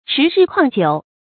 迟日旷久 chí rì kuàng jiǔ
迟日旷久发音
成语注音ㄔㄧˊ ㄖㄧˋ ㄎㄨㄤˋ ㄐㄧㄨˇ